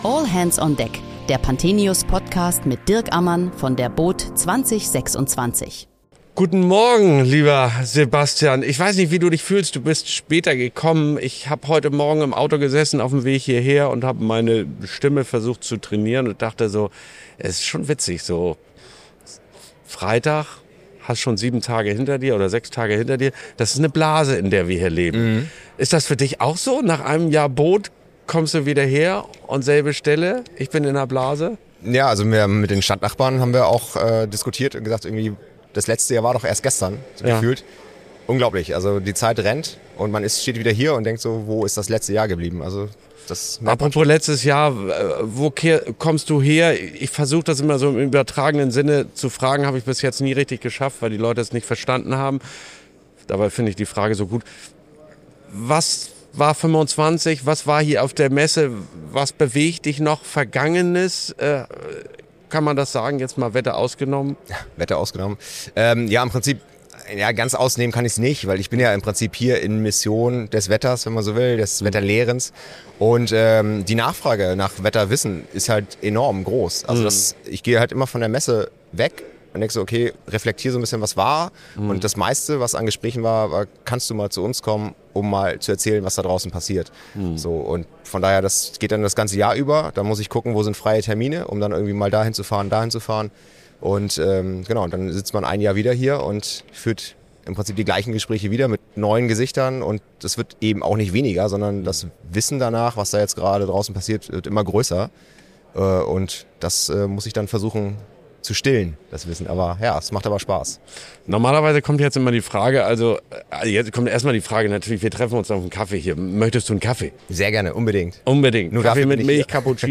Während der boot Düsseldorf 2026 sprechen wir täglich mit Gästen aus der Branche über aktuelle und kontroverse Themen rund um den Wassersport.